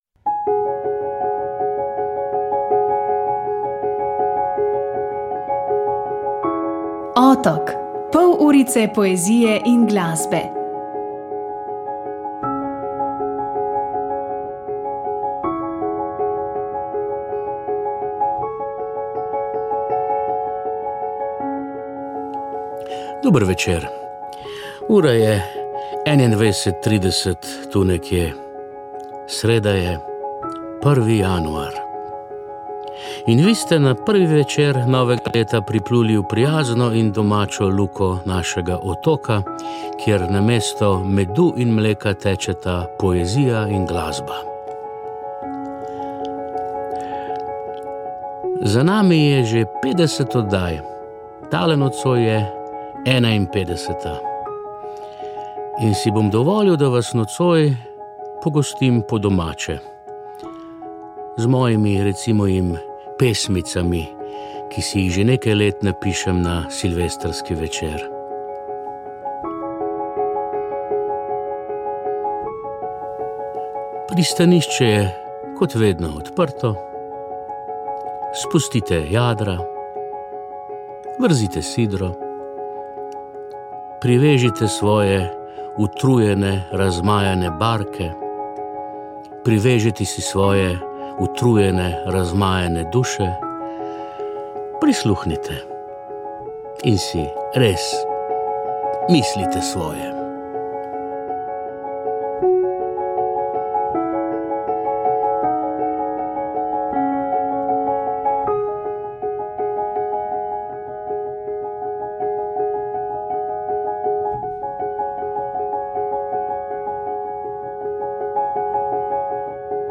O knjigi in p. Corteseju so na predstavitvi v Ljubljani